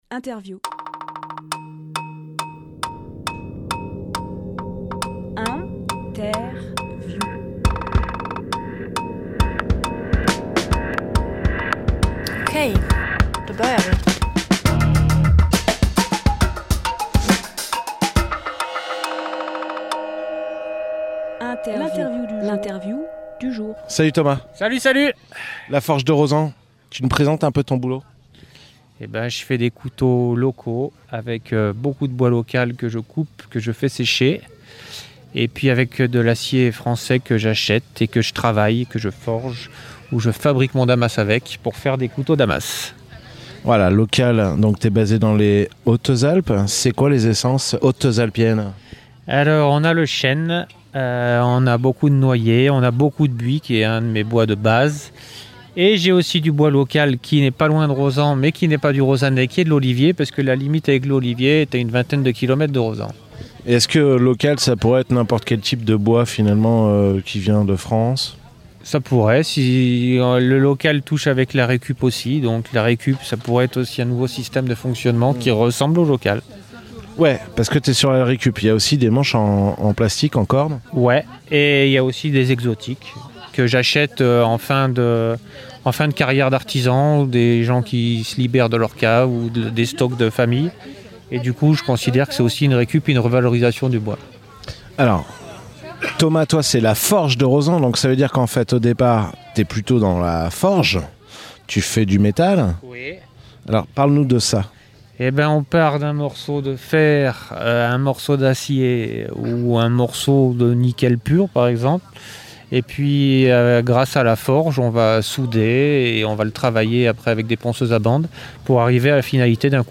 Emission - Interview Tous En Bottes : La Forge de Rosans Publié le 31 mai 2023 Partager sur…